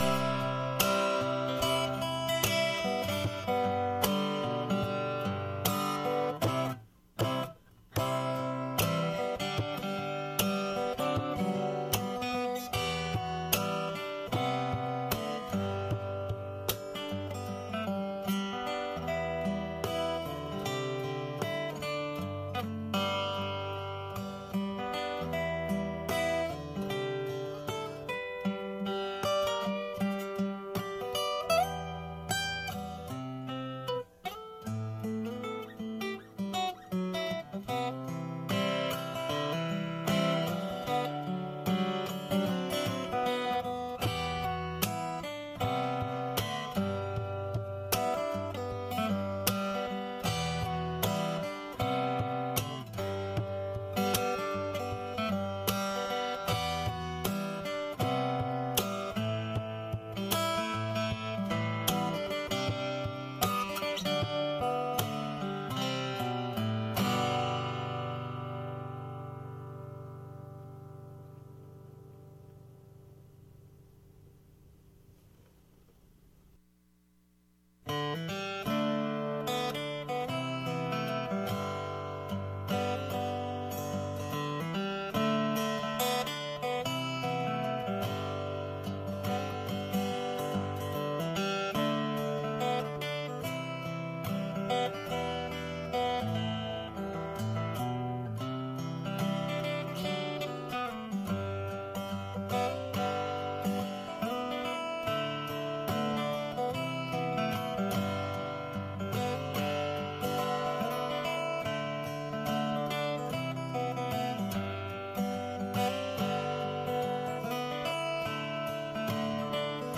SESSÃO SOLENE